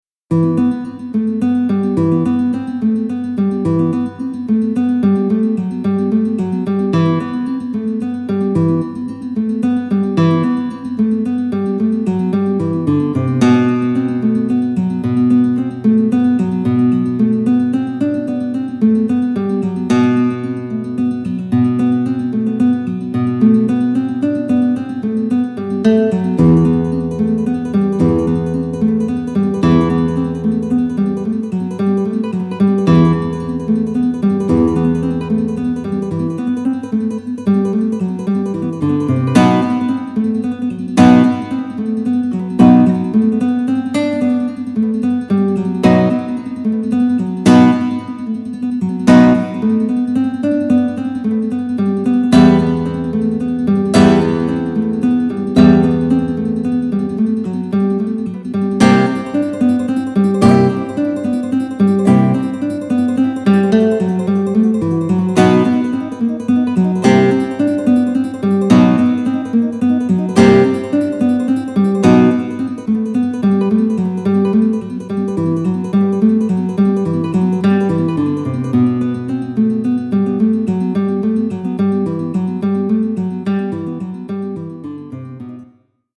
These MP3's were recorded on a Tyros II, using the built in harddisk recorder, edited and encoded with a computer.
Classic Guitar (Leyenda)